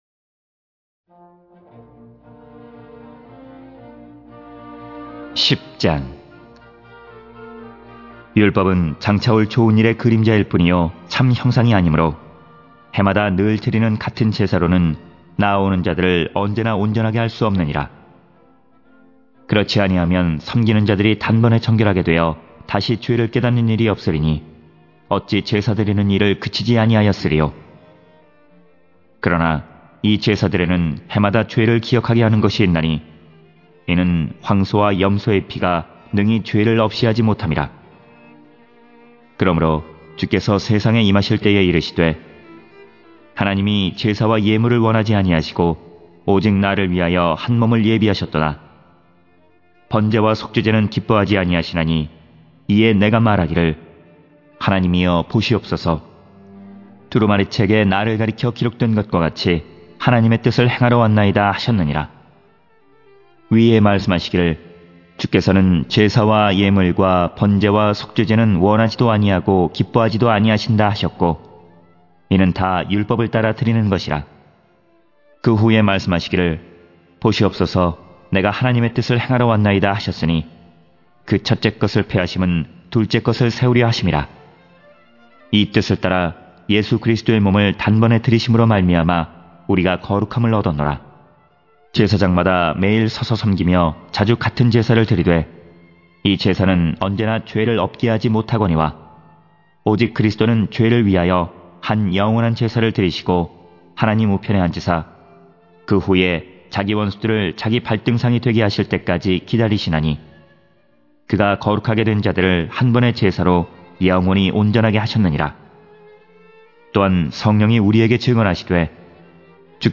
성경 듣기(개역개정) + 성경 읽기(쉬운성경)